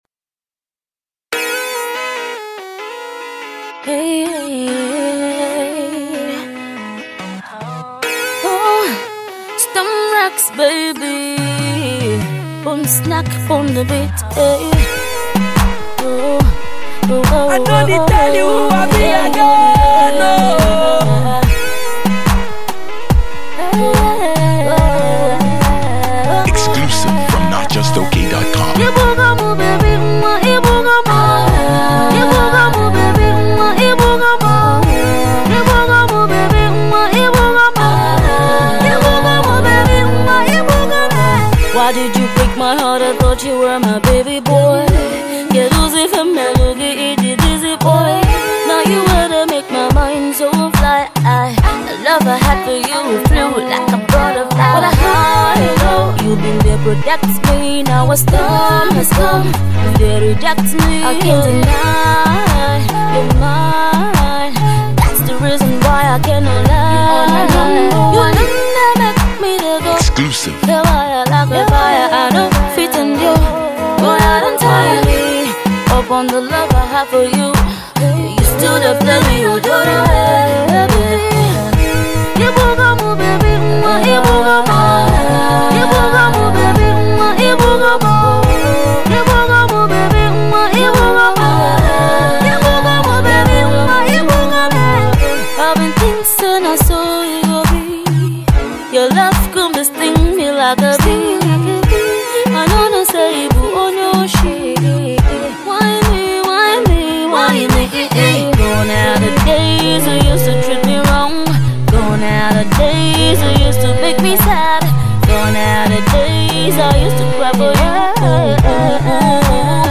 It’s a laid-back tune for those calm moments.